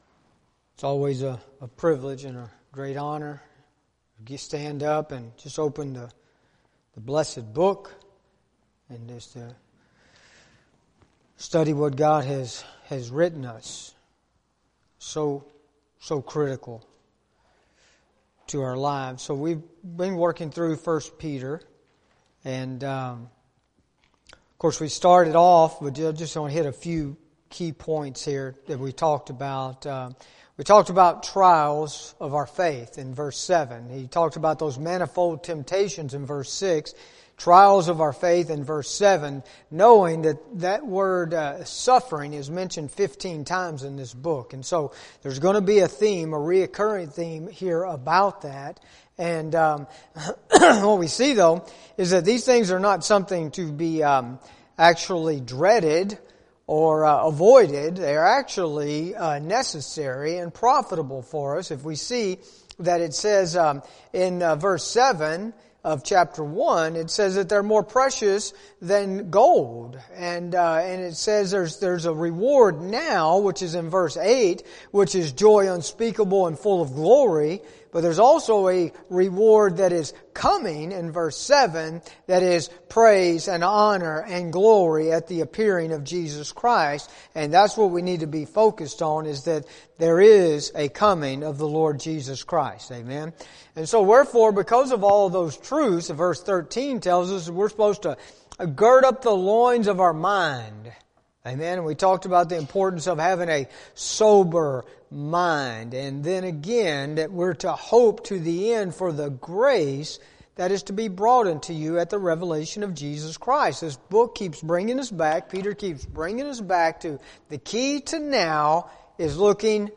Sunday School